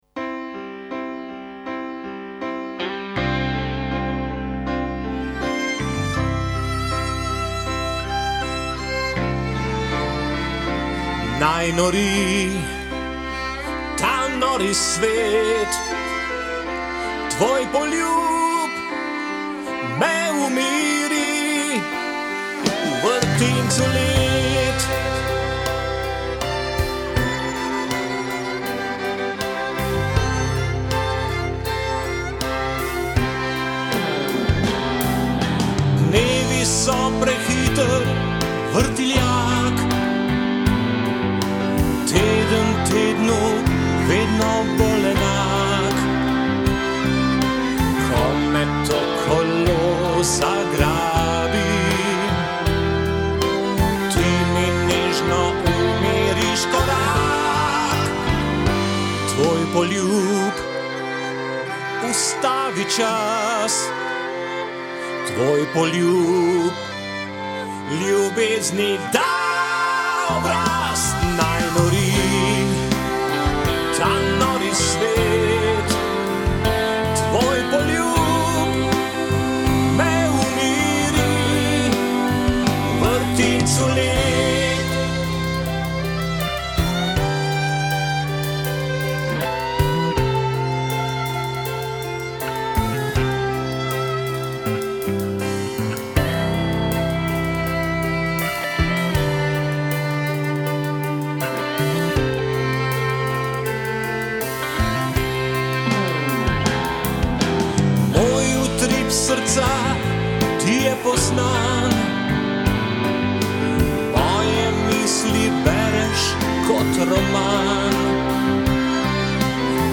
godalni kvartet